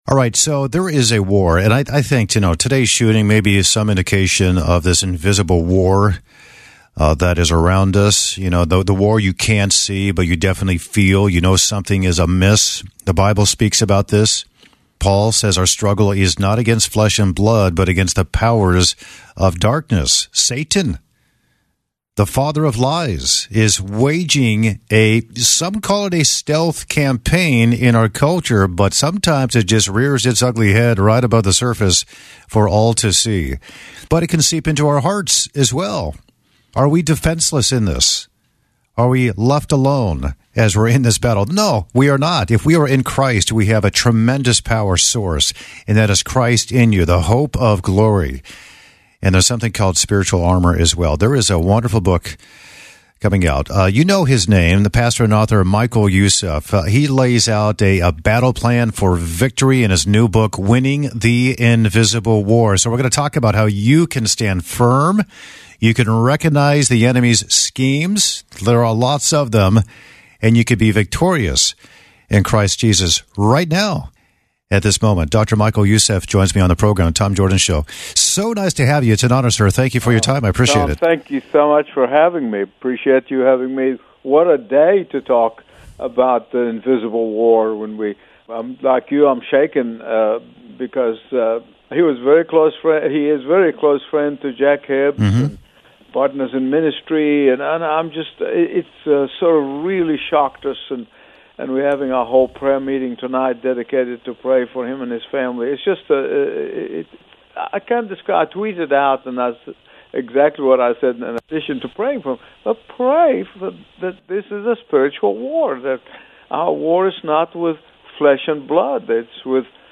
But mid-interview, unexpected news broke: Charlie Kirk, a brother in Christ and fellow voice in the cultural battle, had been assassinated.